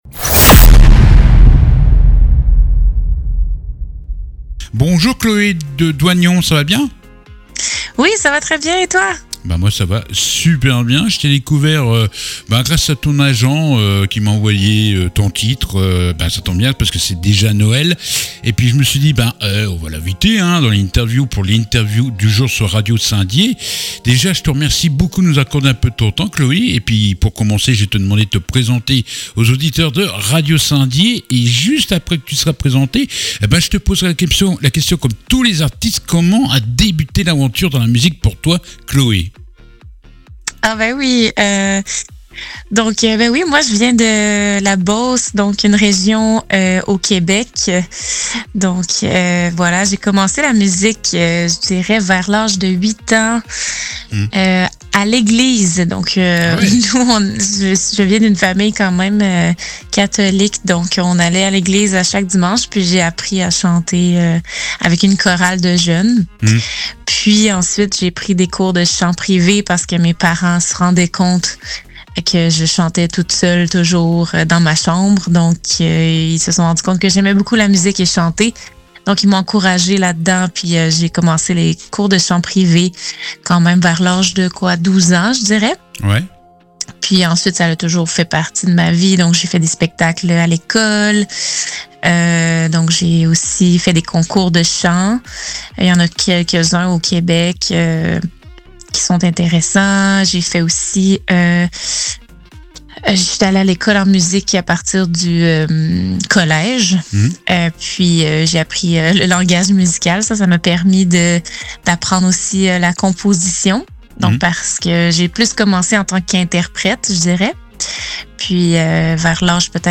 L'interview du jour